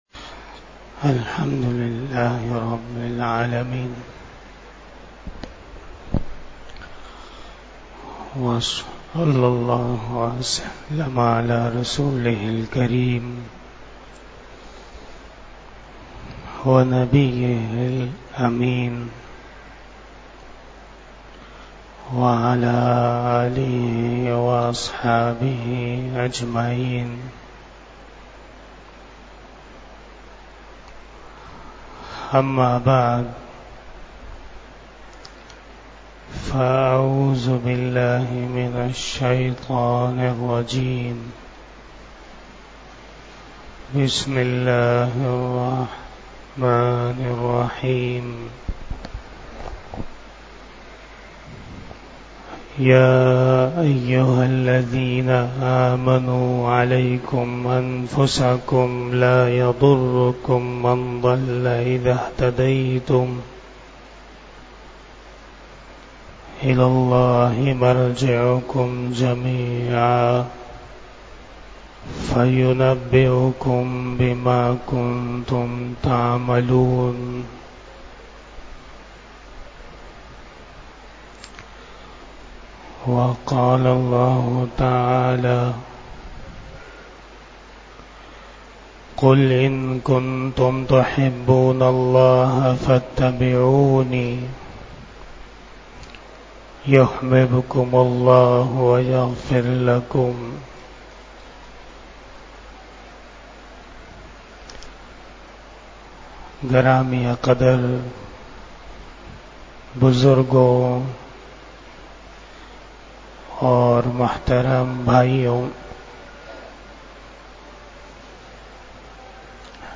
40 Bayan E Jummah 06 October 2023 (19 Rabi Ul Awal 1445 HJ)